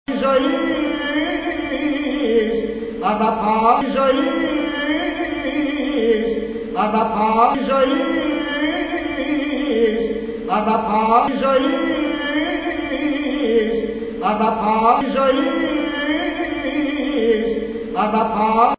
Lar_SK_Akathitstos_Solo_Faltso_Trill.mp3